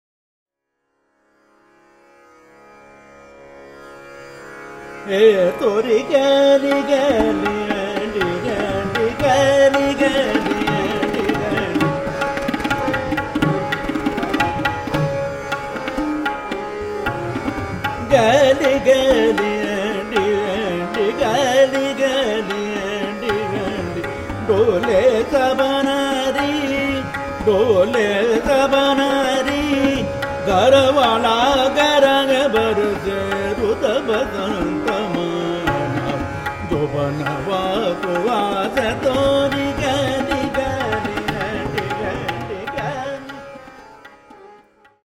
Drut bandish in Ektal